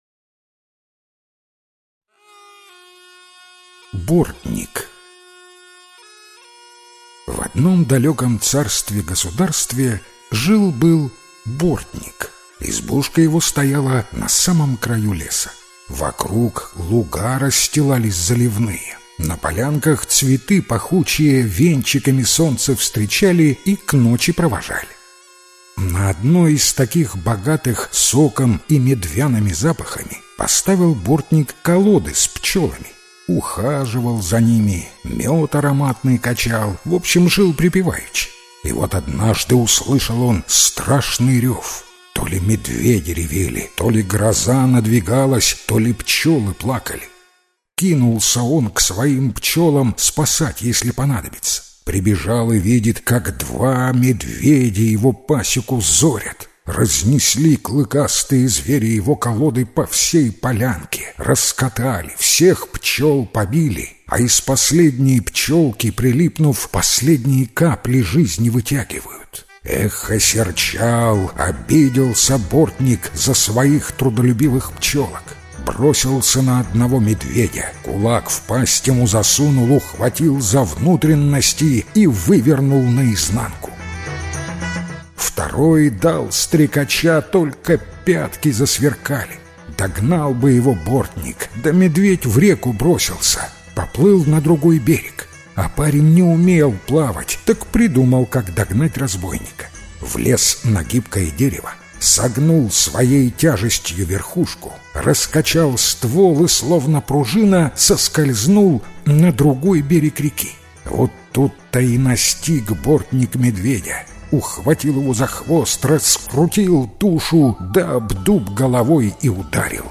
Бортник - белорусская аудиосказка - слушать онлайн